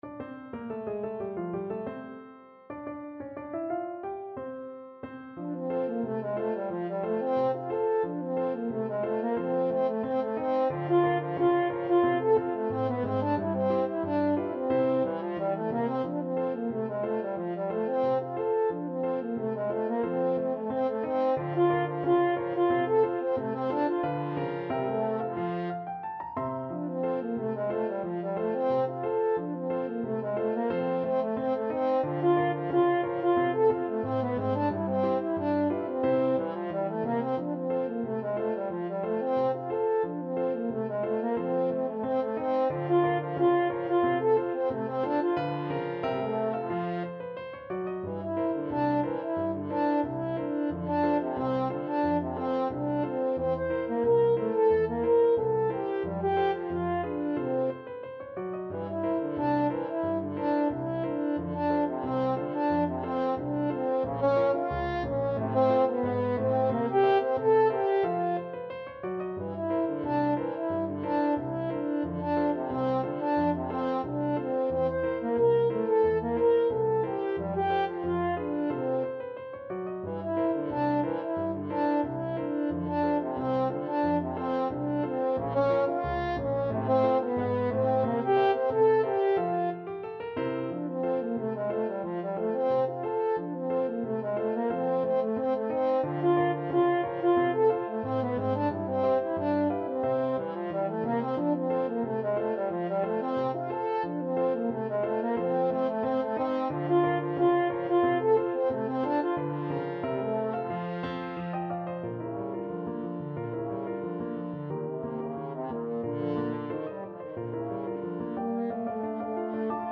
French Horn
F major (Sounding Pitch) C major (French Horn in F) (View more F major Music for French Horn )
Slow march tempo Slow March tempo. = 90
2/4 (View more 2/4 Music)
Jazz (View more Jazz French Horn Music)
pineapple_rag_HN.mp3